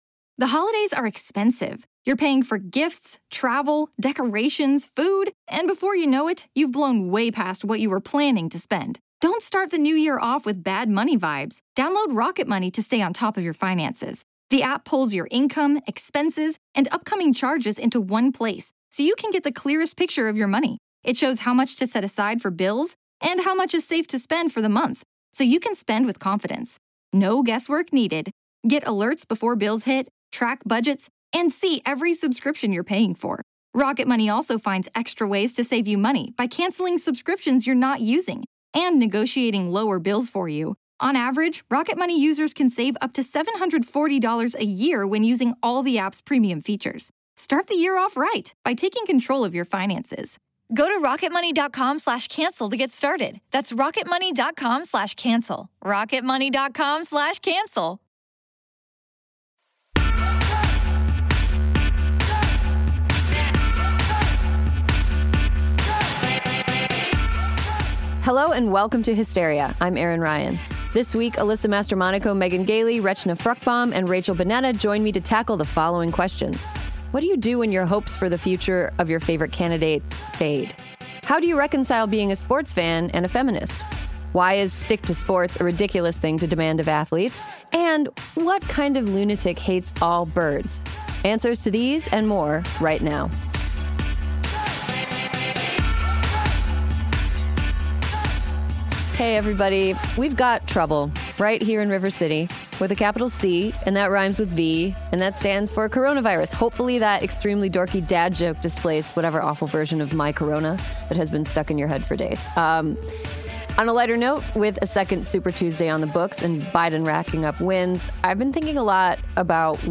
join in-studio to tackle (pun intended!) the following questions: How do you reconcile being a sports fan and a feminist? Why is “stick to sports” a ridiculous thing to demand of athletes?